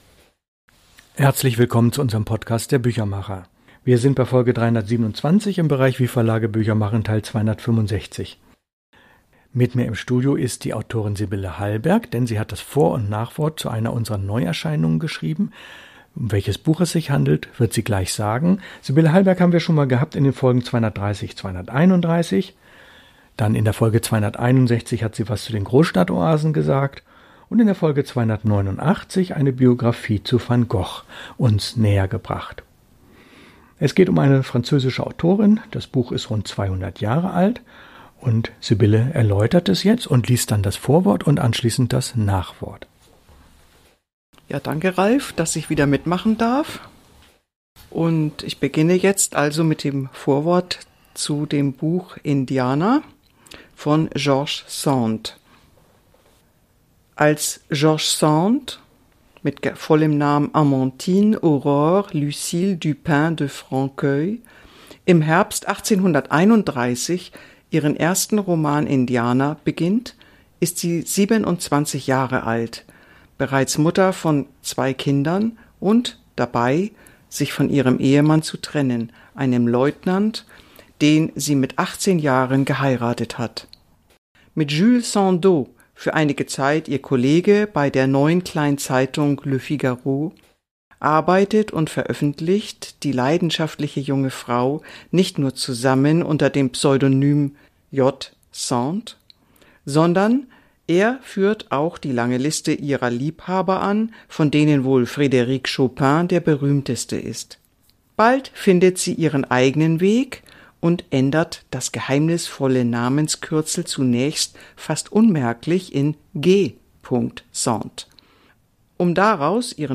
Lesung aus unserer Neuerscheinung.